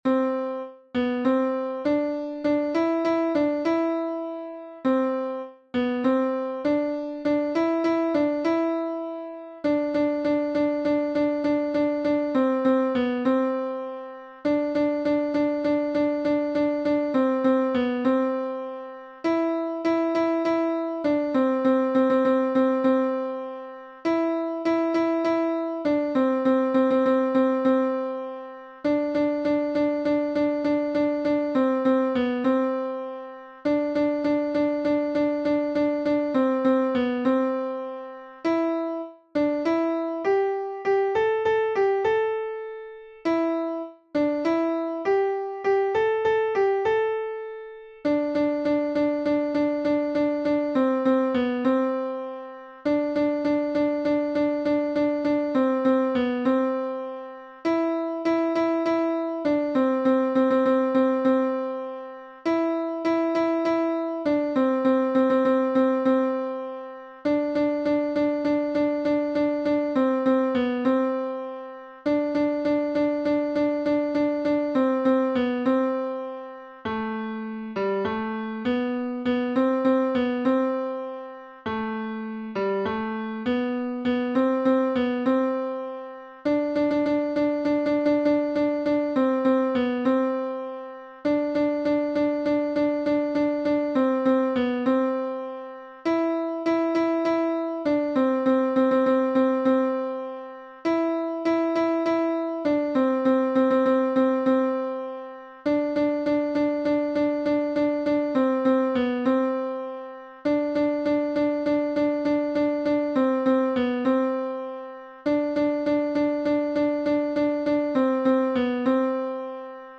Alto 1 (version piano